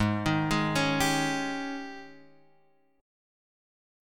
G# Major 7th Suspended 4th
G#M7sus4 chord {4 x 1 1 2 3} chord